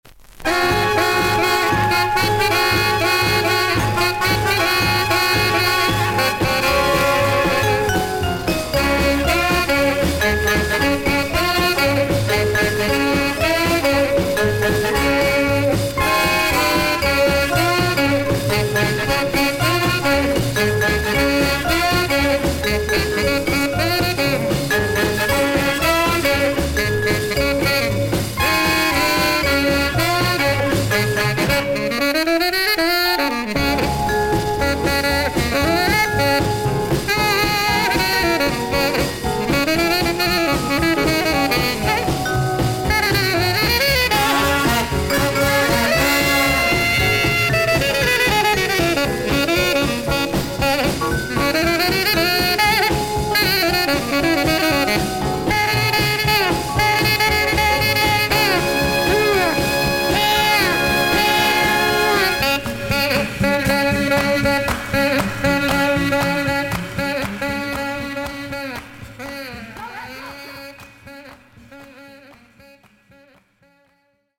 少々軽いパチノイズの箇所あり。クリアな音です。
R&Bサックス奏者。
鮮烈なビートのジャンプR&B/R&Rナンバーを荒々しく吹き捲ってます。